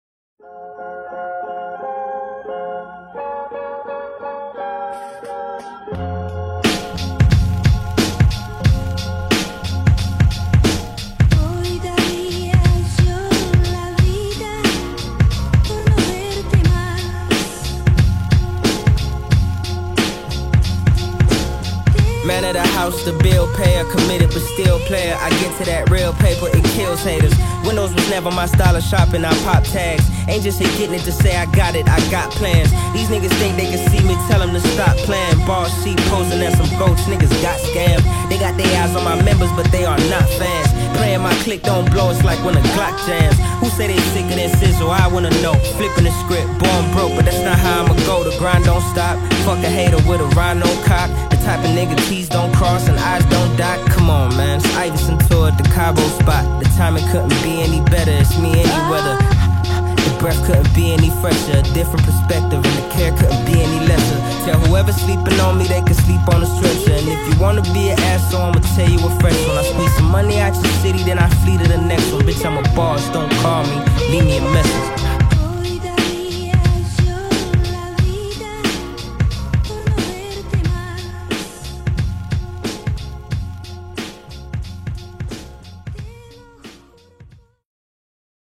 Talented South African Singer